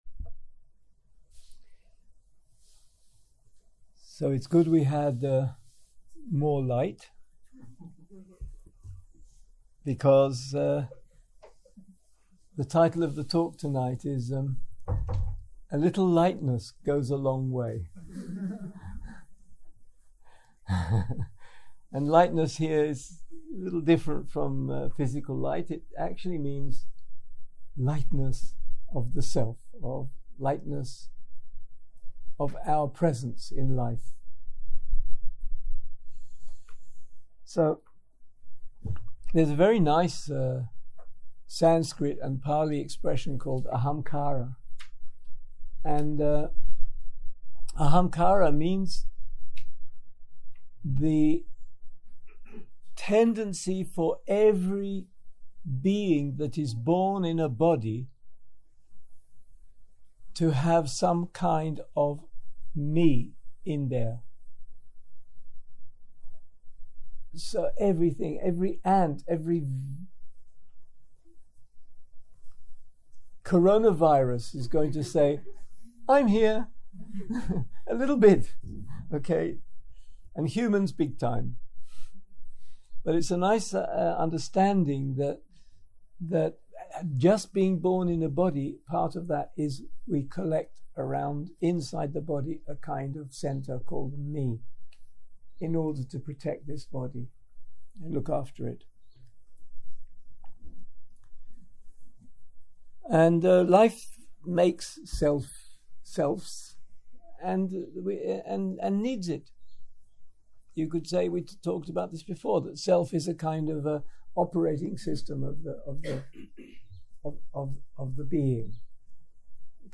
יום 5 - ערב - שיחת דהרמה - A little lightness goes a long way - הקלטה 12
סוג ההקלטה: שיחות דהרמה